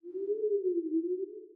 Babushka / audio / sfx / Battle / Enemies / SFX_Mavka_Voice_02.wav
SFX_Mavka_Voice_02.wav